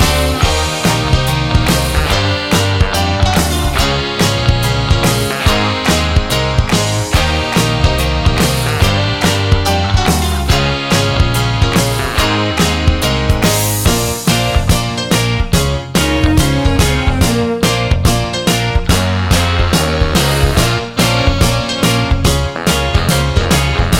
no Backing Vocals Ska 3:28 Buy £1.50